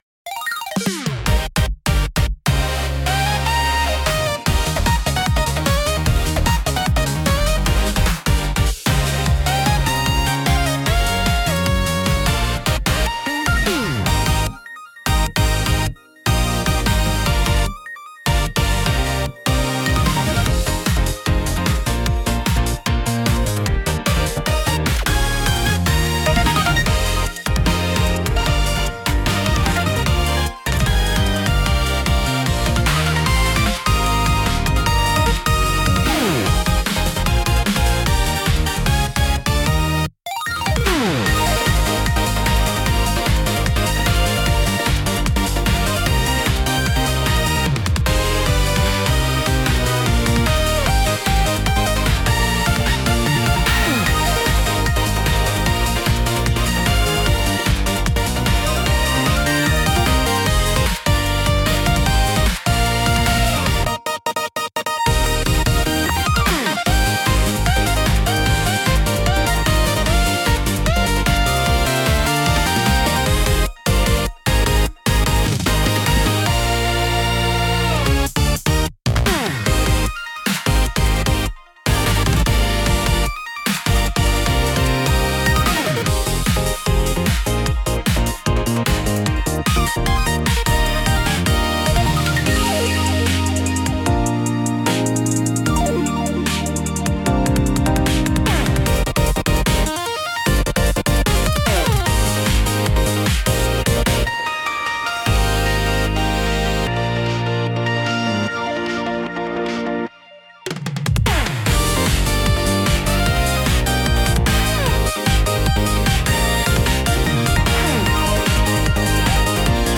聴く人の気分を高め、緊張と興奮を引き立てるダイナミックなジャンルです。